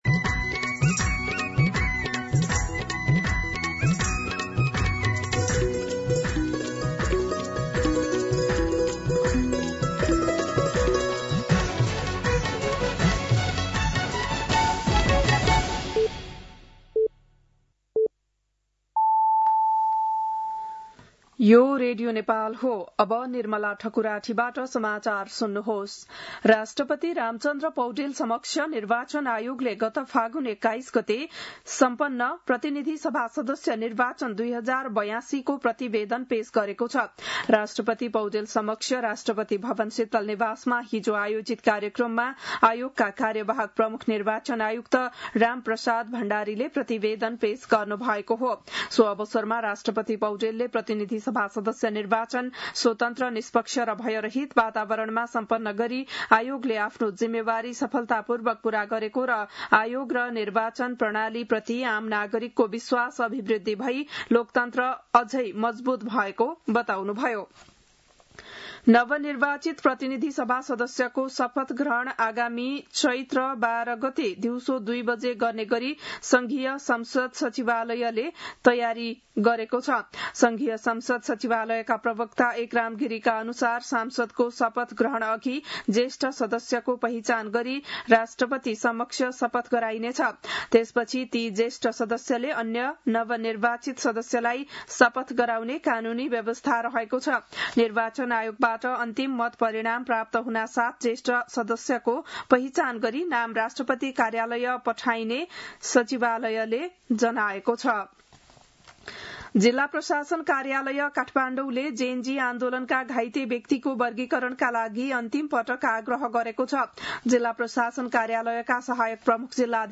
बिहान ११ बजेको नेपाली समाचार : ६ चैत , २०८२
11-am-News-12-6.mp3